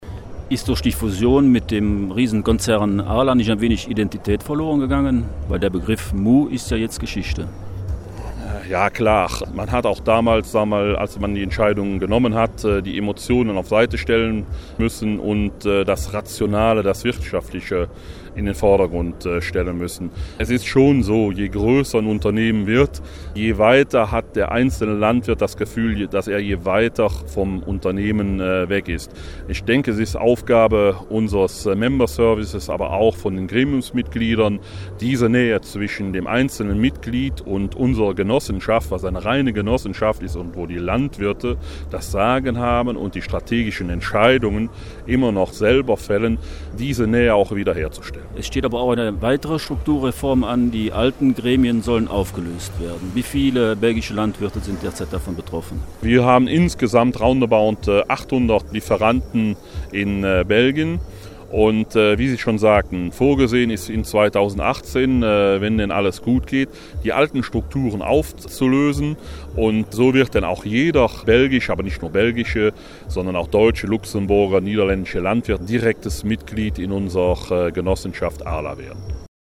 sprach mit Landwirt